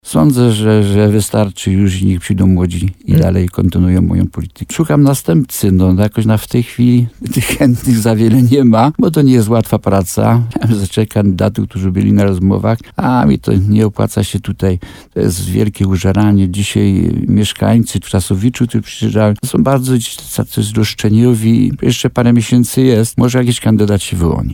Józef Tobiasz nie chce być już wójtem gminy Gródek nad Dunajcem. Po 13 latach piastowania tego stanowiska poinformował w programie Słowo za Słowo na antenie RDN Nowy Sącz, że nie zamierza starać się już o fotel włodarza gminy w nadchodzących wyborach.